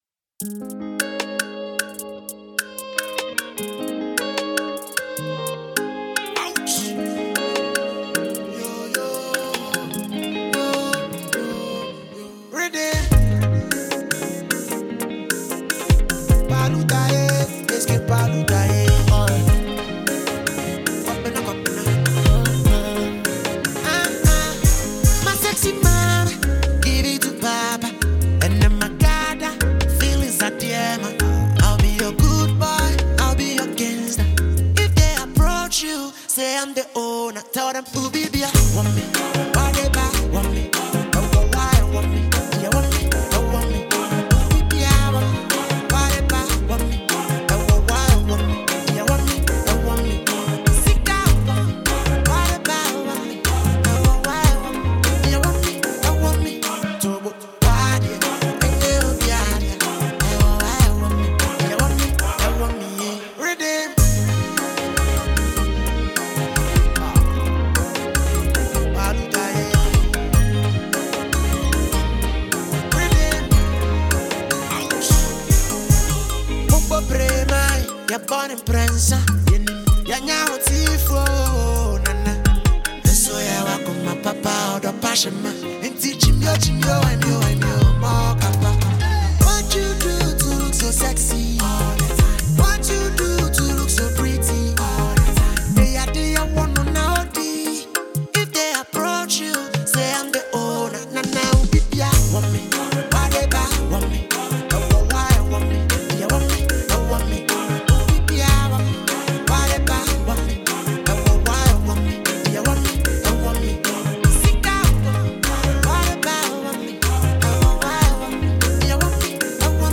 heartfelt and melodious Ghanaian track
With a mix of highlife and contemporary Afrobeat influences
• Genre: Highlife / Afrobeat